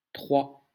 Audio pronunciation file from the Lingua Libre project.